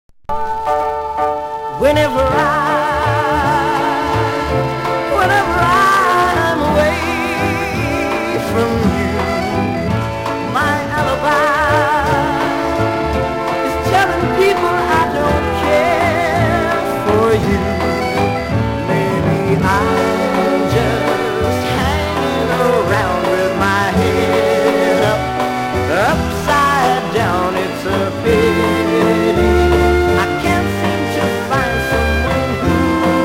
バンドネオンの調べがまるで欧州の何処の街を思わせるメランコリックな仕上り。